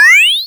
Add sound effects for player actions and collectables
child_pickup.wav